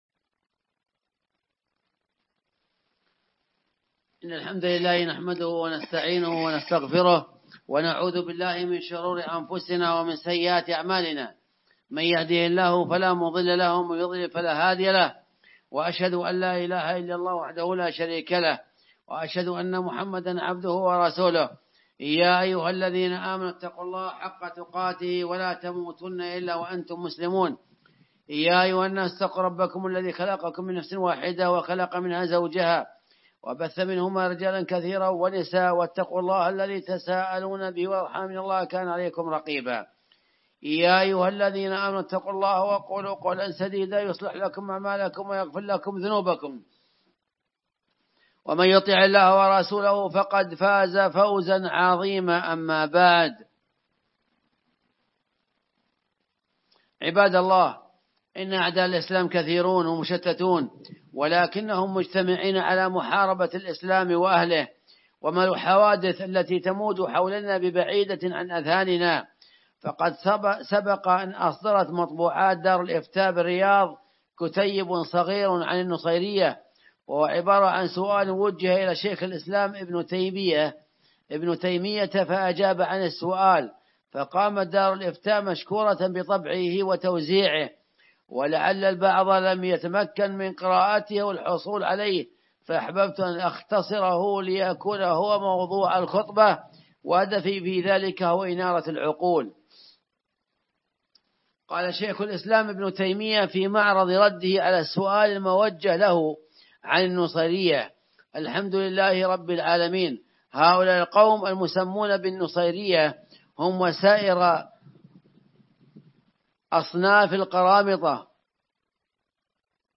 الخطب
التصنيف : الخطب الصوتية تاريخ النشر